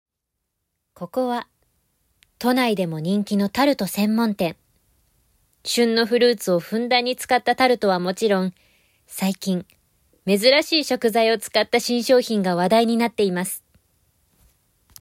ボイスサンプル
ナレーション@